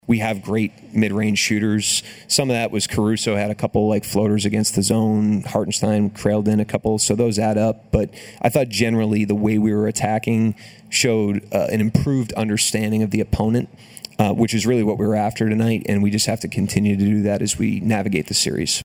Mark Daigneault says his team’s shot selection while making a run was a big reason for some early separation.